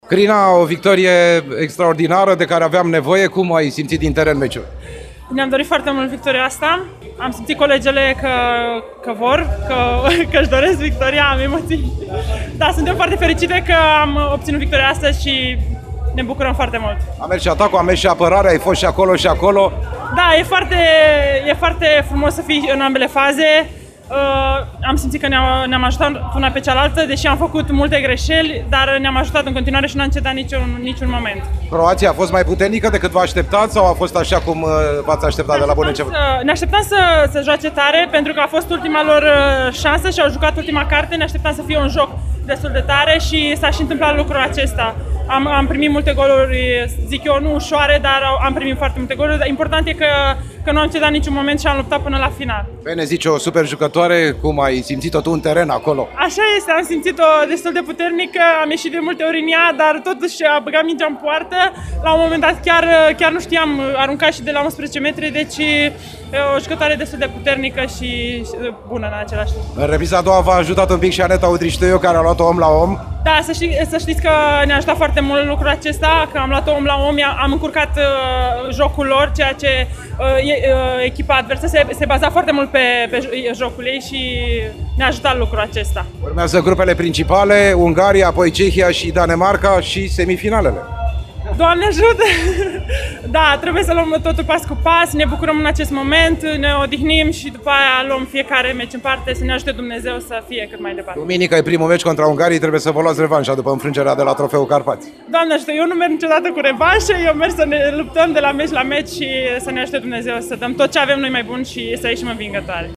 a stat de vorbă cu una dintre principalele jucătoare de apărare, Crina Pintea.